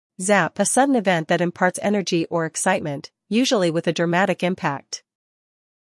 英音/ zæp / 美音/ zæp /